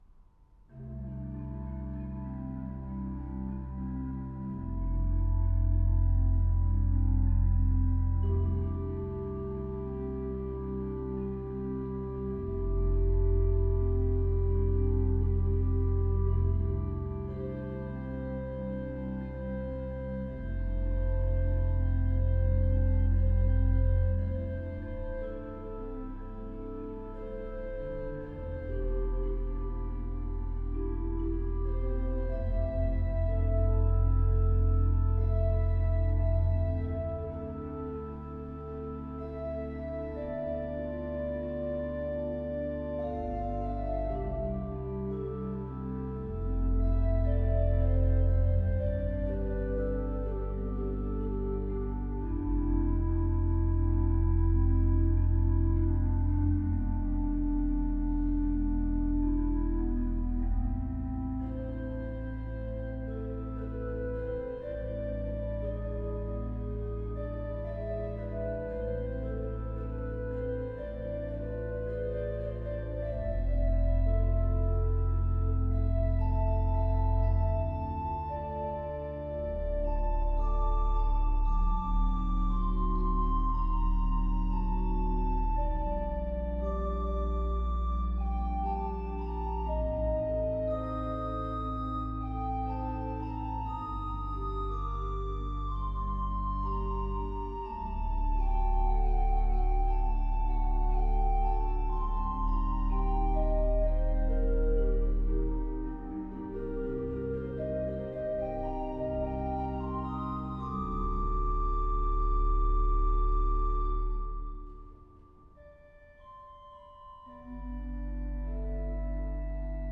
at Canongate Kirk in Edinburgh